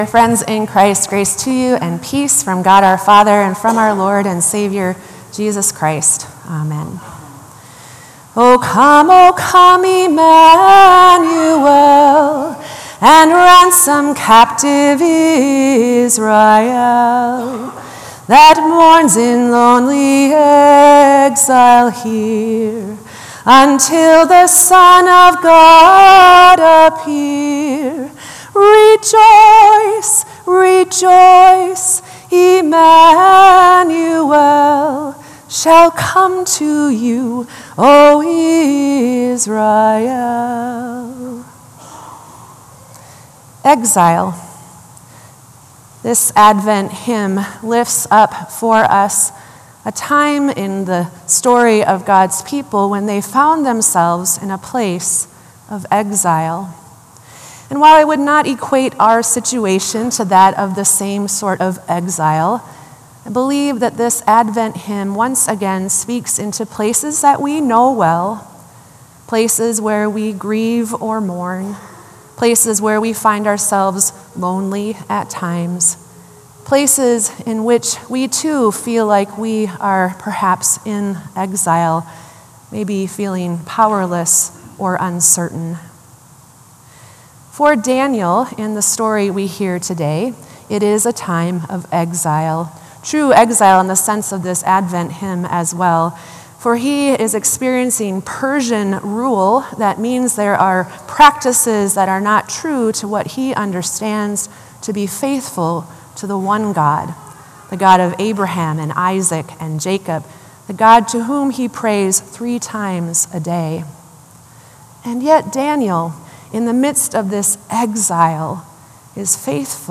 Sermon “What Was I Thinking?”